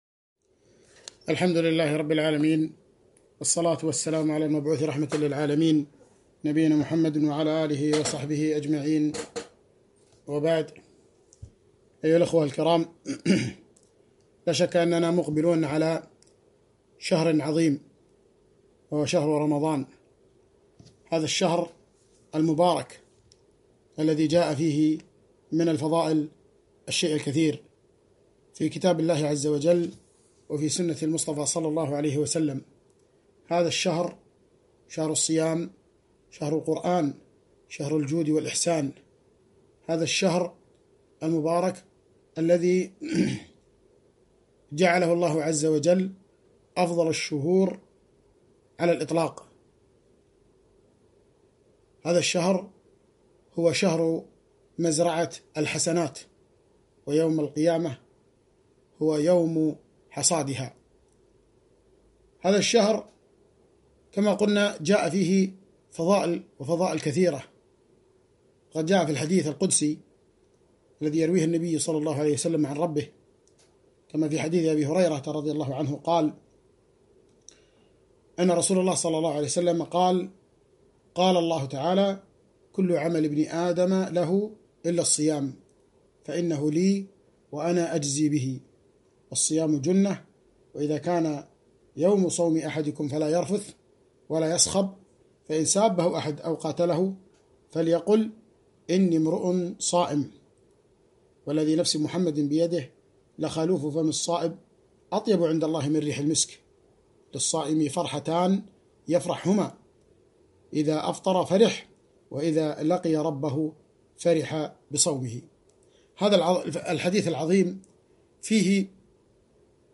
للصائم فرحتان - كلمة